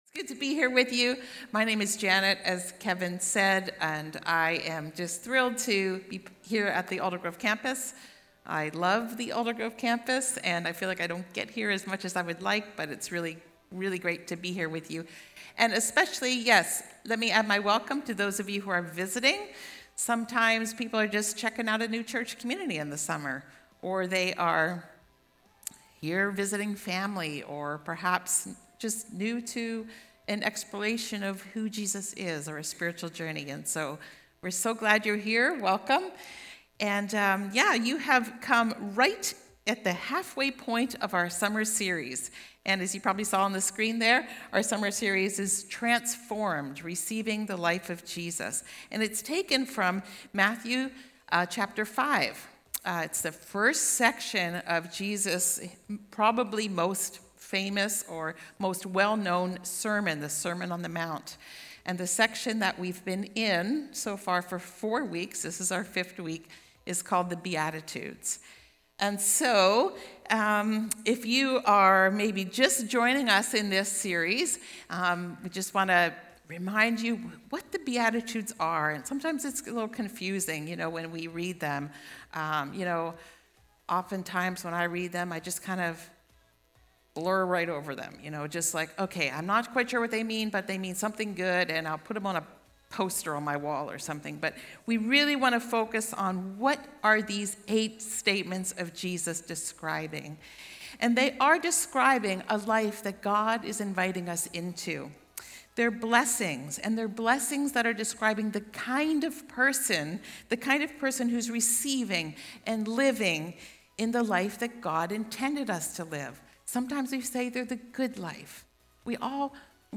Aldergrove Sermons | North Langley Community Church